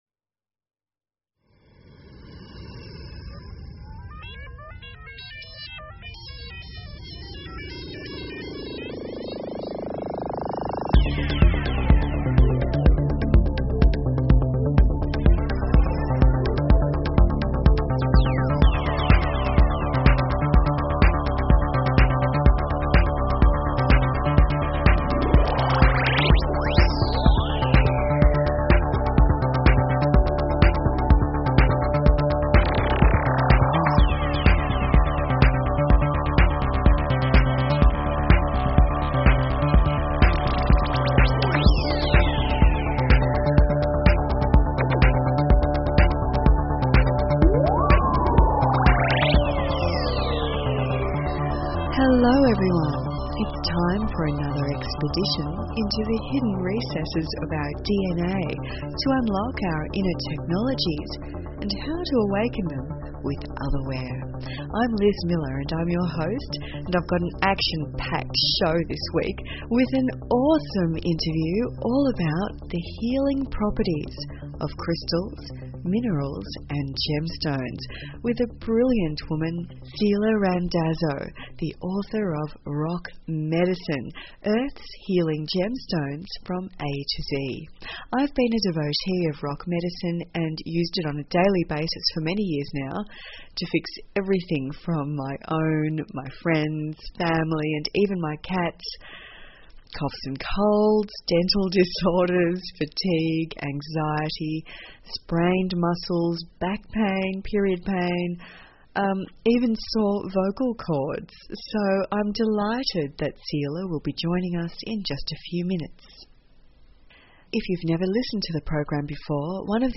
Talk Show Episode, Audio Podcast, Otherware and Courtesy of BBS Radio on , show guests , about , categorized as